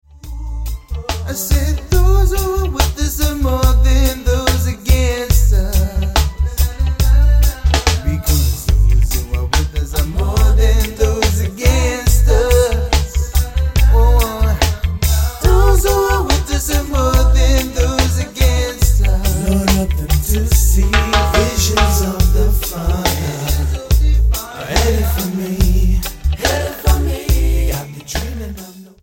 STYLE: Reggae
Tonga gospel reggae rules!